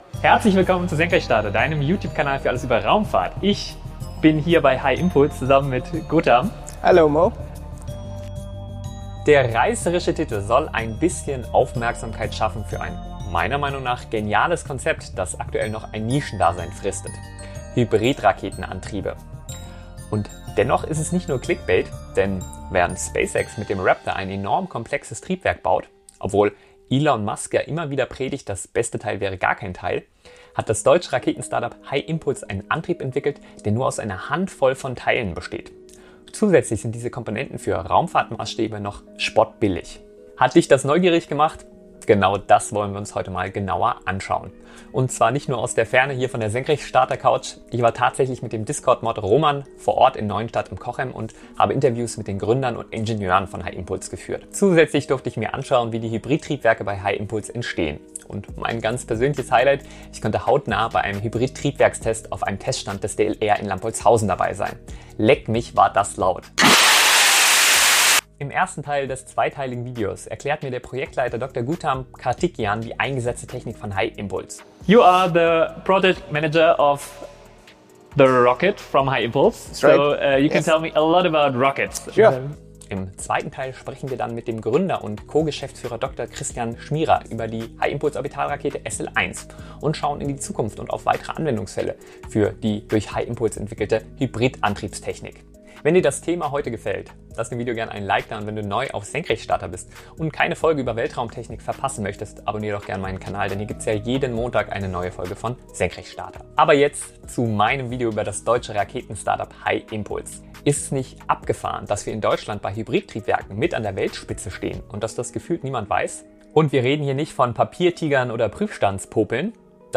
Ich habe Interviews mit den Gründern und Ingenieuren von HyImpulse geführt.
Und mein ganz persönliches Highlight: Ich konnte hautnah bei einem Hybrid-Triebwerkstest auf einem Teststand des DLR in Lampoldshausen dabei sein.